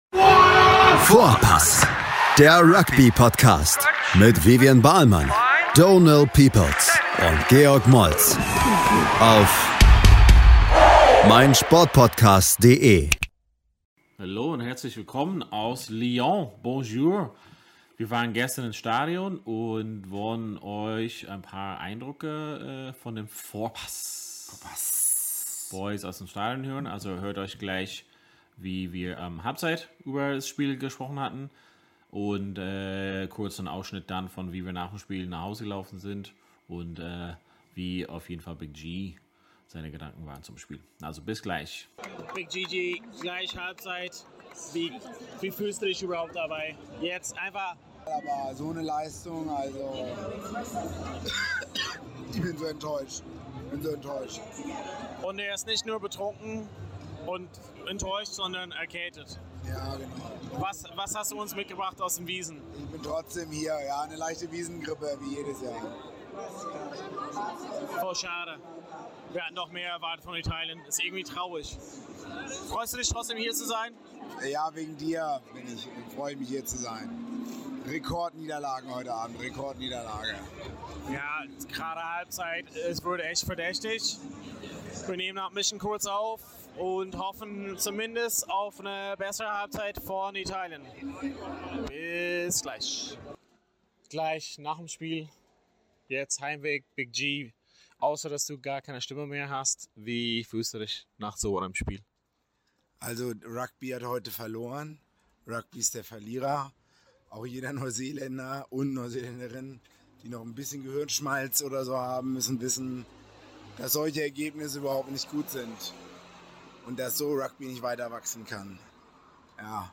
Erste Eindrücke aus dem Stadion während des Halbzeit und direkt danach die enttäuschenden Stimmen nach dem Spiel. Viele, auch Vorpass, hatten sich mehr vom Spiel erhofft, was in einem Desaster für Italien endete.